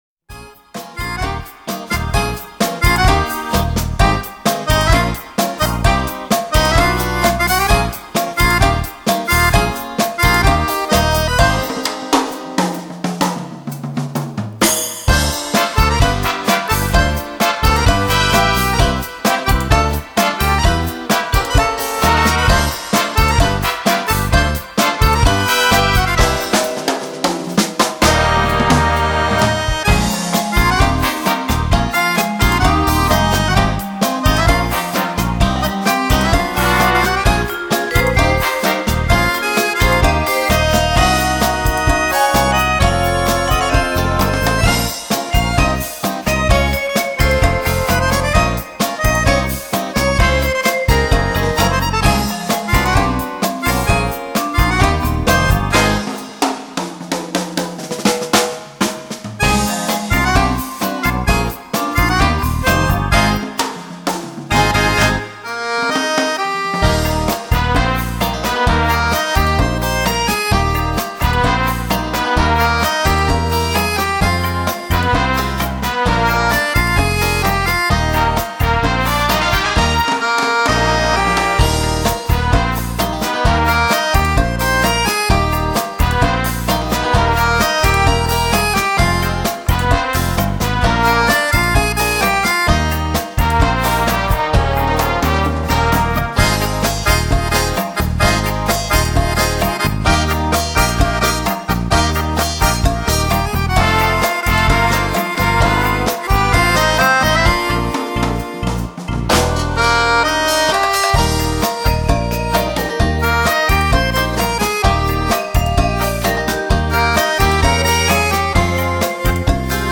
Cha cha cha